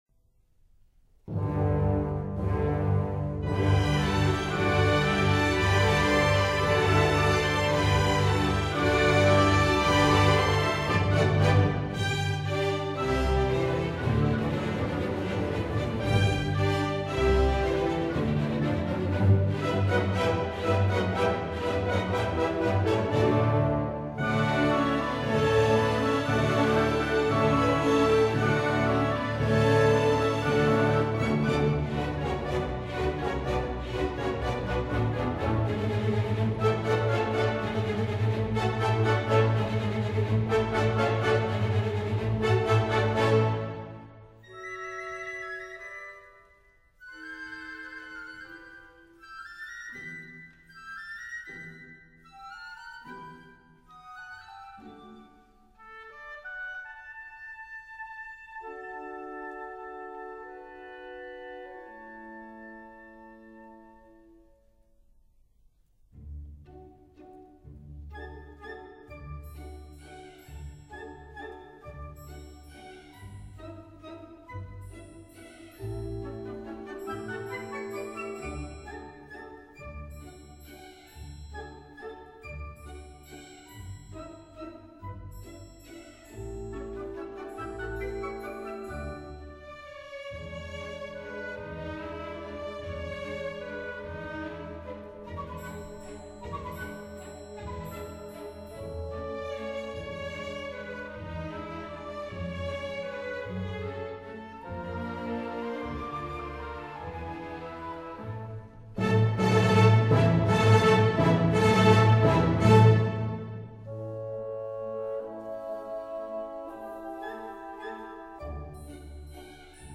华尔兹 <06:25>